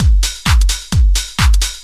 Index of /90_sSampleCDs/Ueberschall - Techno Trance Essentials/02-29 DRUMLOOPS/TE06-09.LOOP-TRANCE/TE08.LOOP-TRANCE3